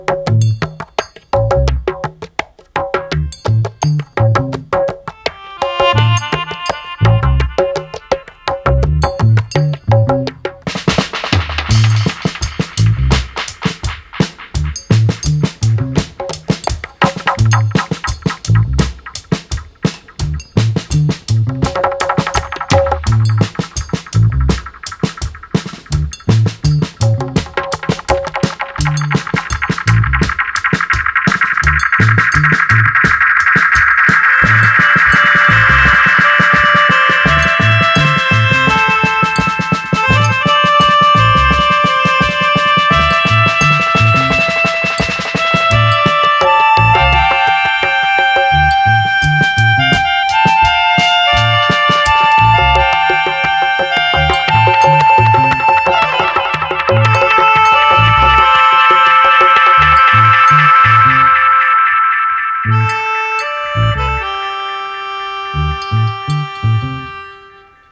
オールアナログにこだわり、すべての楽器を自分で演奏し、16 トラックのオープンリール MTR への録音